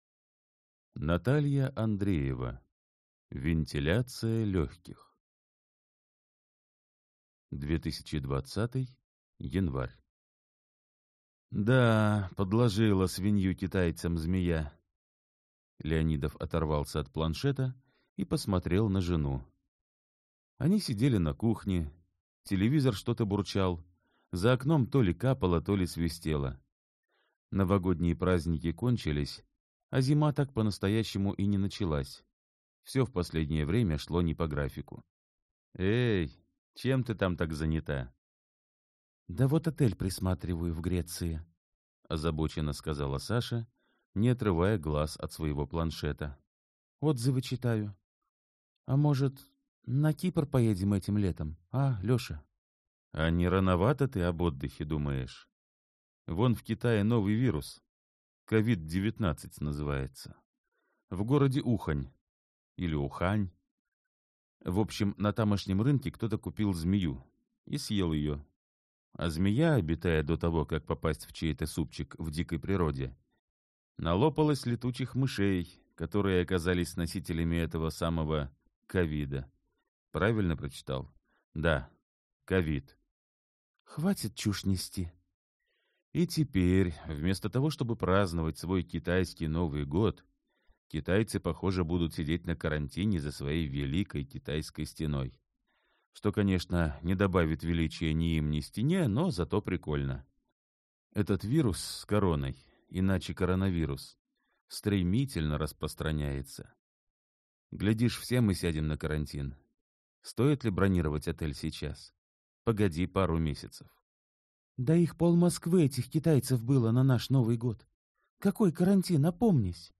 Аудиокнига Вентиляция легких | Библиотека аудиокниг